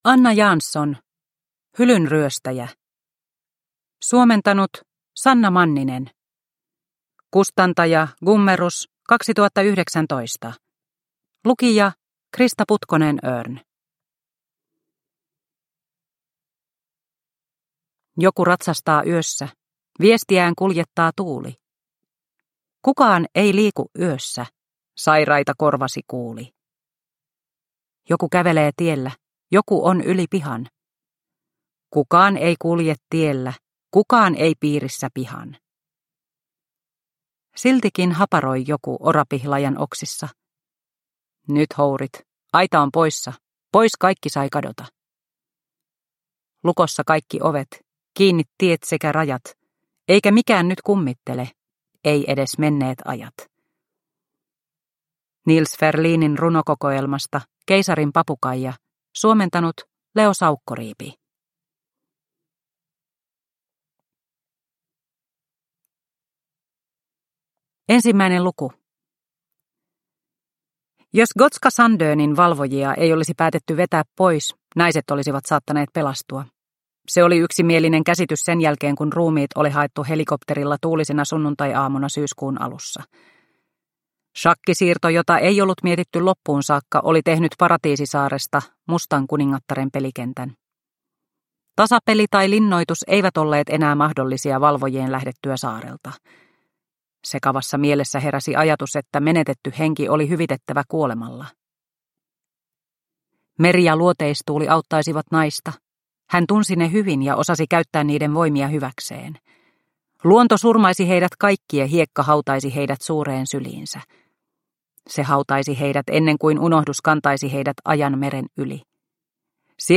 Hylynryöstäjä – Ljudbok – Laddas ner